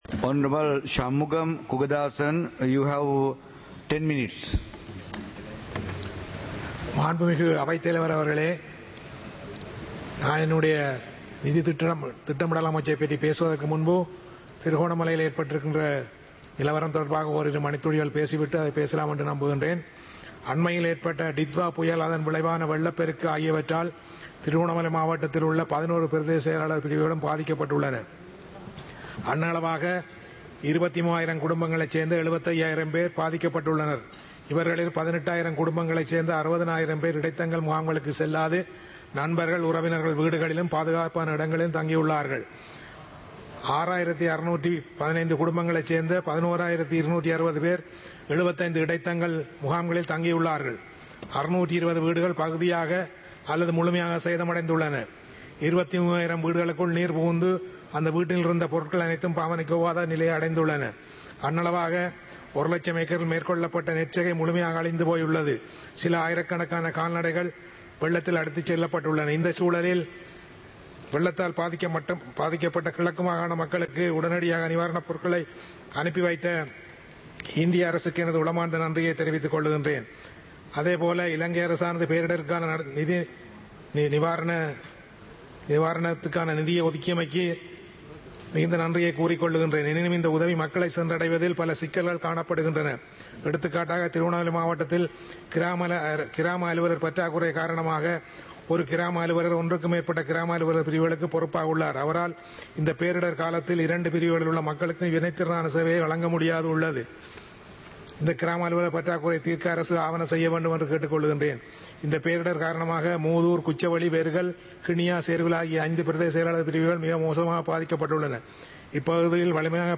Parliament Live - Recorded